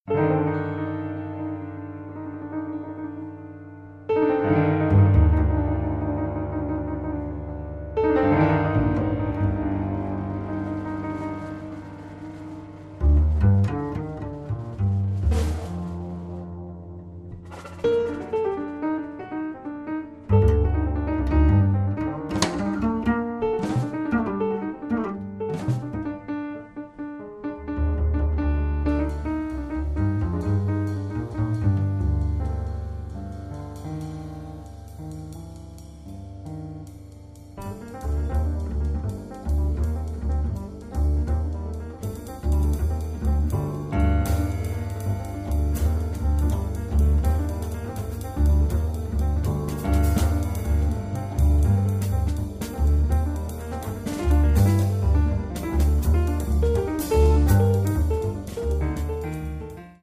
batteria
pianoforte
contrabbasso